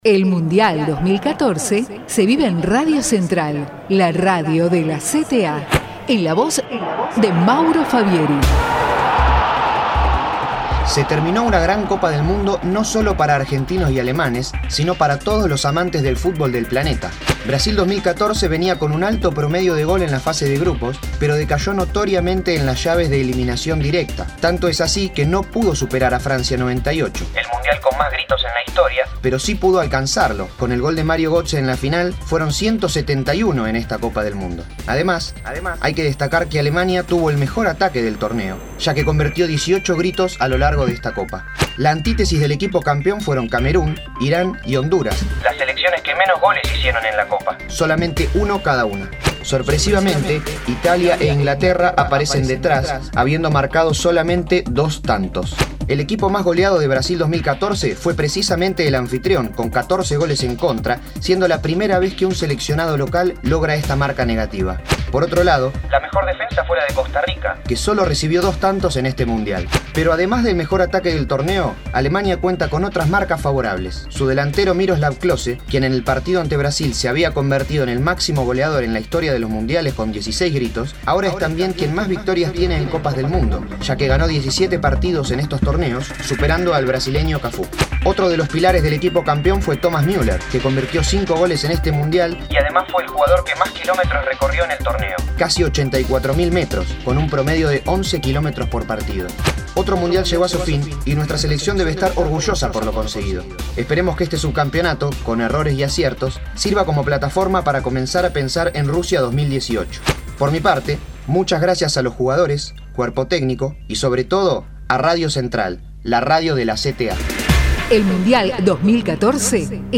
MUNDIAL BRASIL 2014: Micro-Informativo RADIO CENTRAL – Central de Trabajadores y Trabajadoras de la Argentina